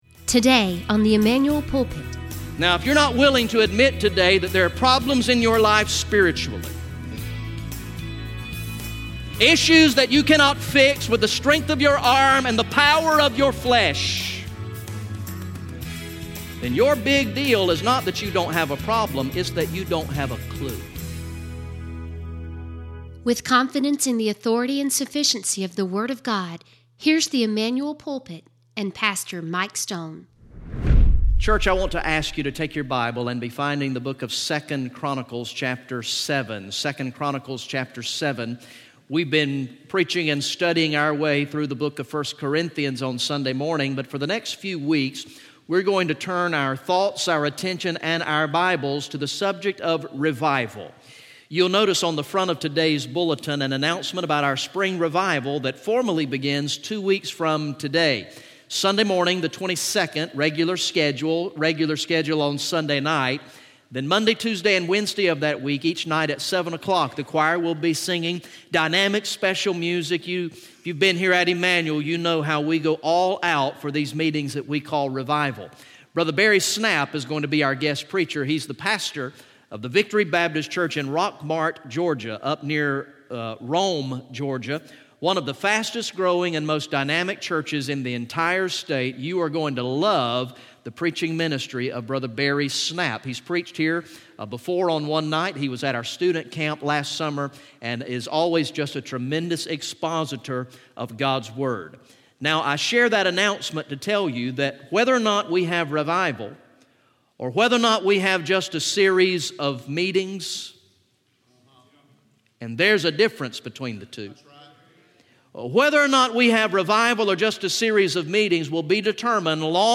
From the morning worship service on Sunday, April 8, 2018